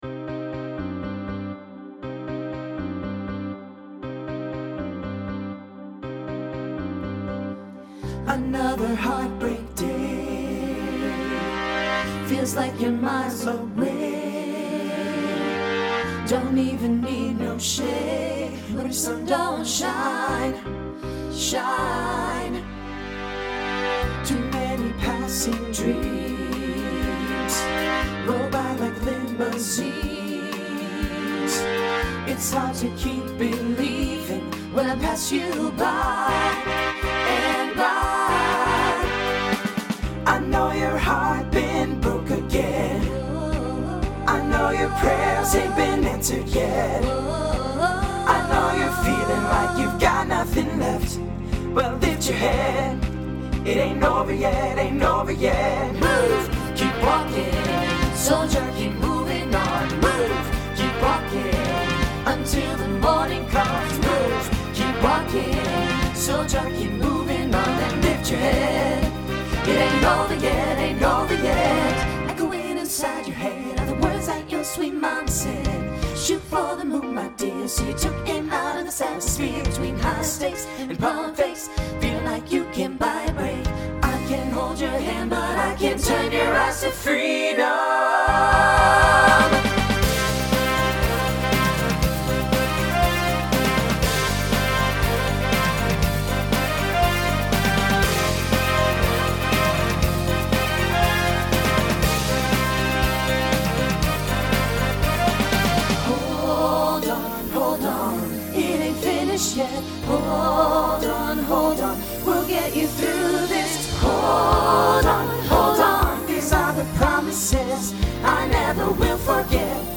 Voicing SATB Instrumental combo Genre Rock
Mid-tempo